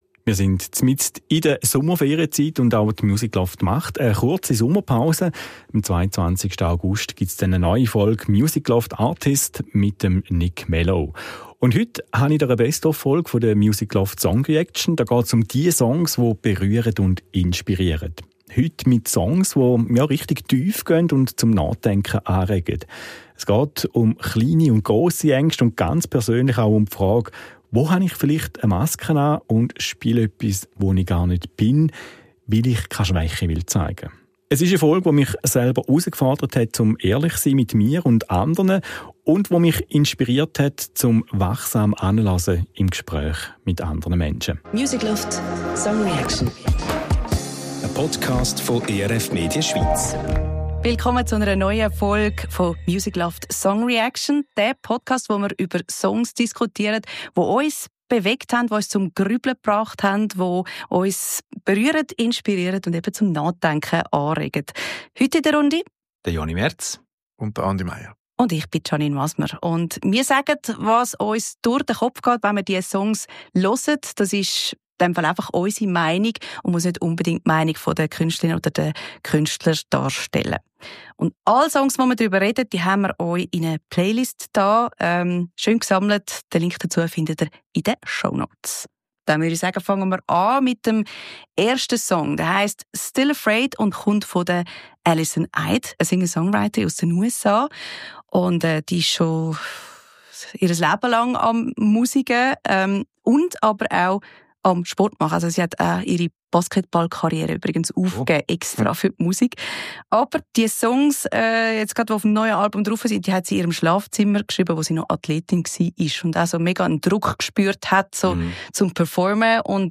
Zu Dritt sitzen wir im Studio und diskutieren über Songs, die uns berührt und inspiriert haben.